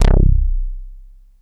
RM12BASS C1.wav